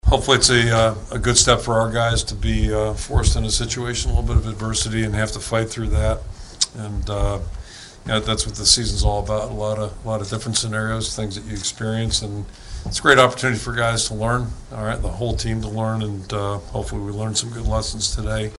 That’s Iowa coach Kirk Ferentz.